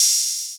YM Open Hat 1.wav